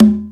80s Digital Conga 01.wav